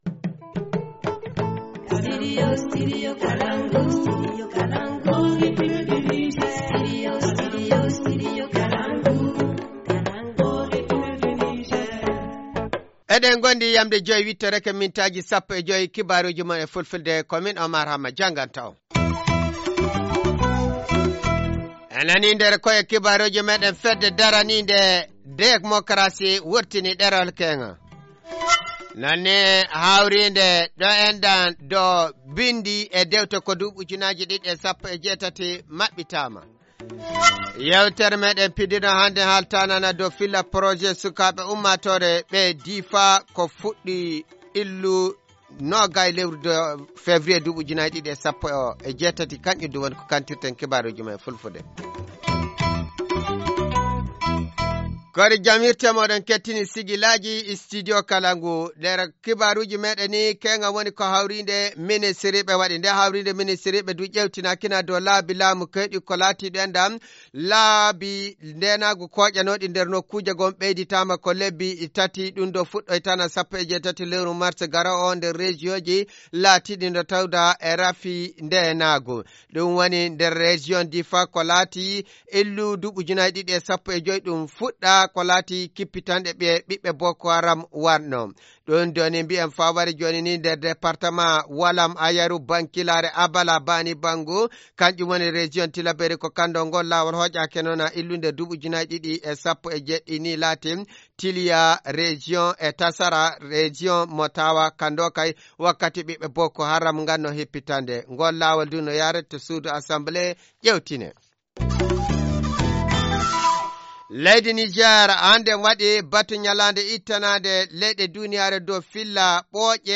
Journal du 9 mars 2018 - Studio Kalangou - Au rythme du Niger